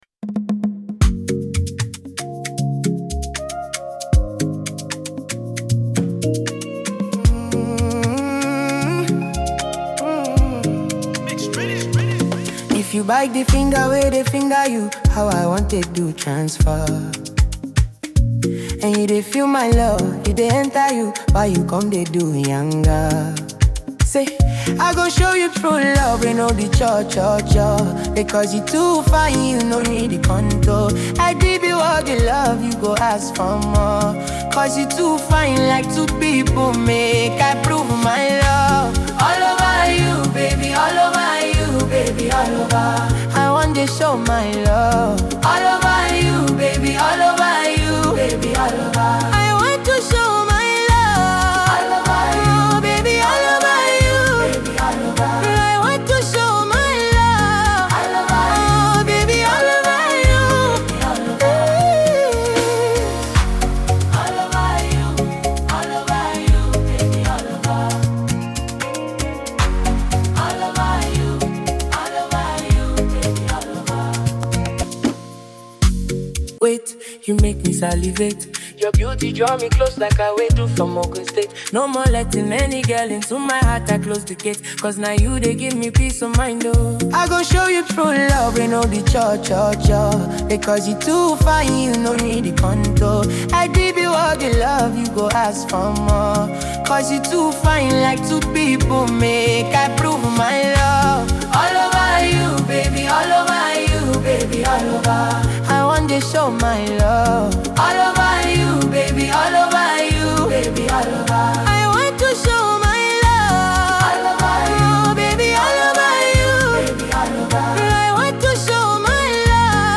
Afrobeats genre, blended with pop sensibilities